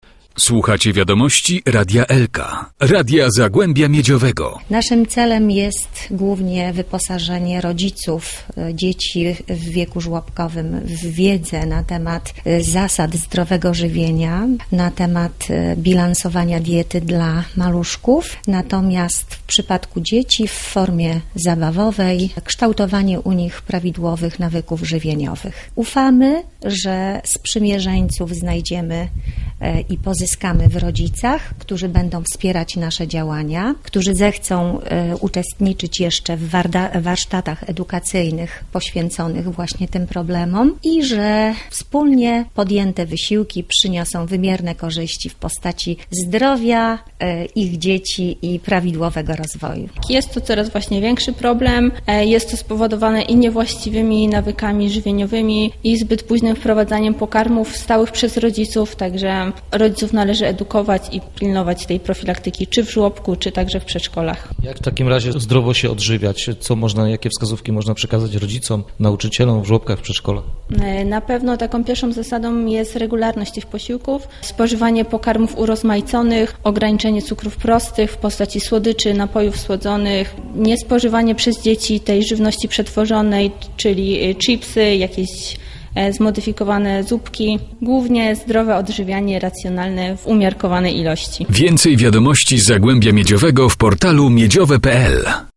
W spotkaniu wzięli udział specjaliści dietetycy, fizjoterapeuci i rodzice maluchów.